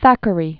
(thăkə-rē, thăkrē), William Makepeace 1811-1863.